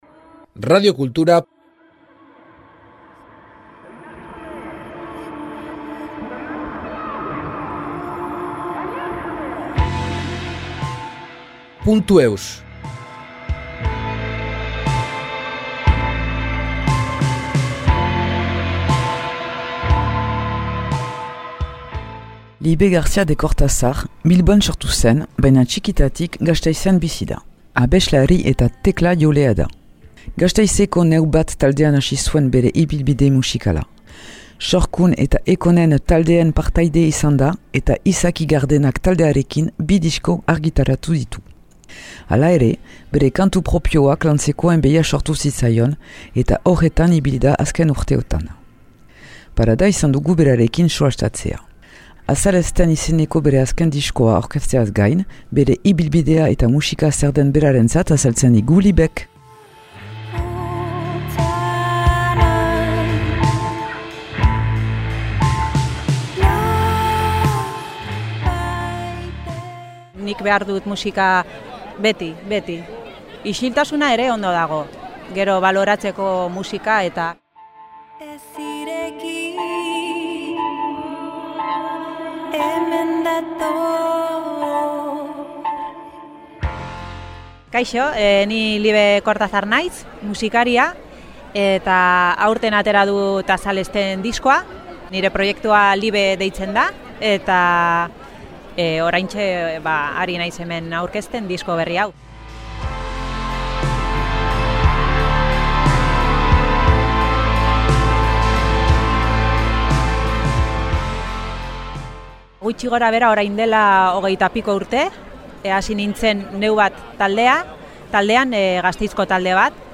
Parada izan dugu berarekin solastatzea.